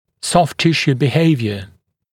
[sɔft ‘tɪʃuː bɪ’heɪvjə] [-sjuː][софт ‘тишу: би’хэйвйэ] [-сйу:]поведение мягких тканей